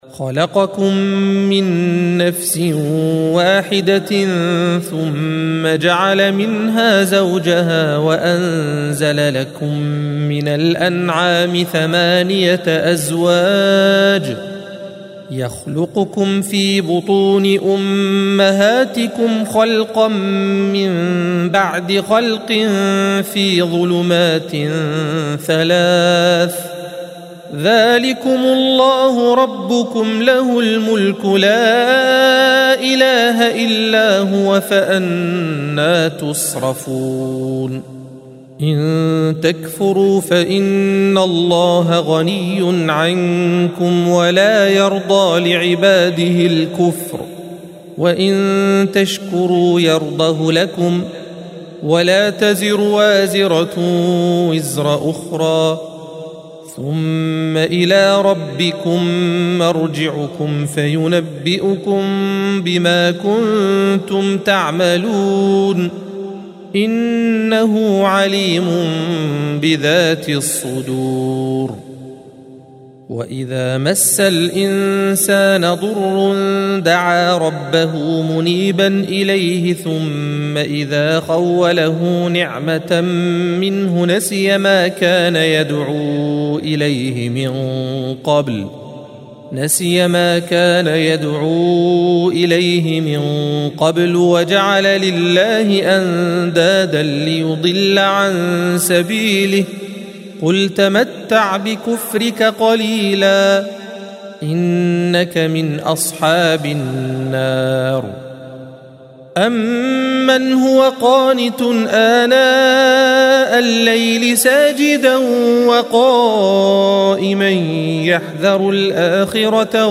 الصفحة 459 - القارئ